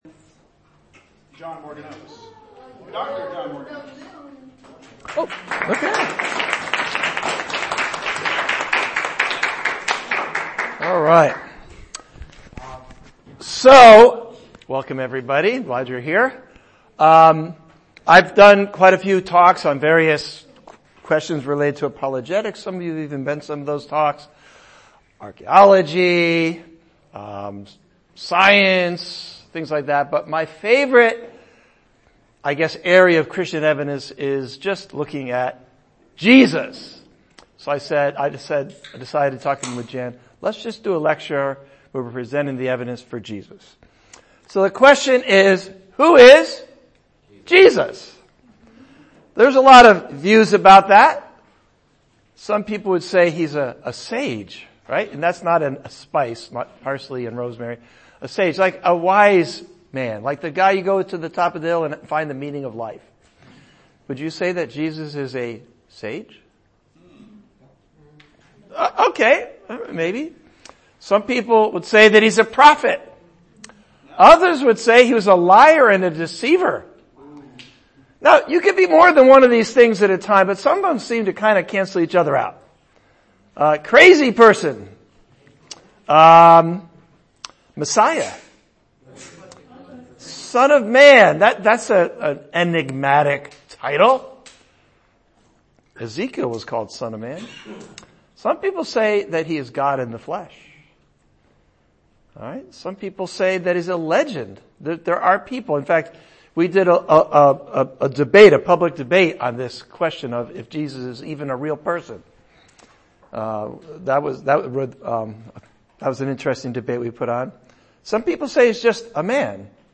Claims of Jesus PPT Who Is Jesus Lecture Audio